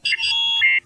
Computer1.wav